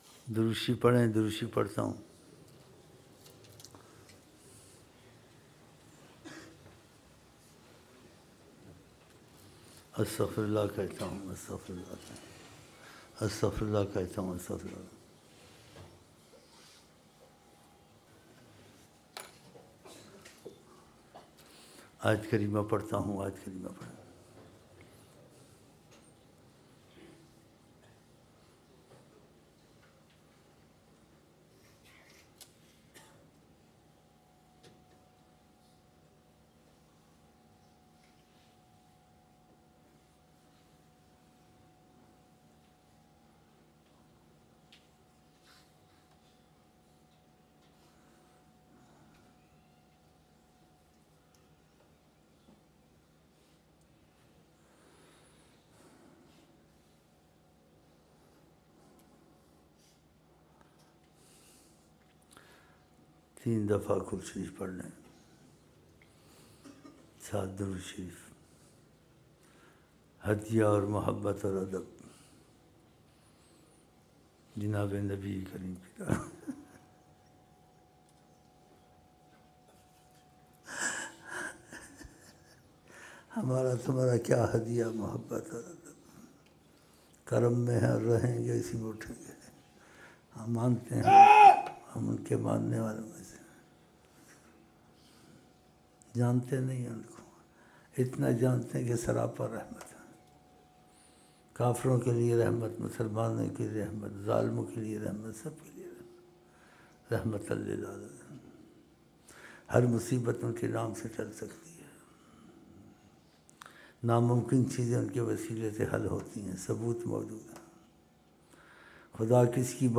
فجر عید الا ضحی محفل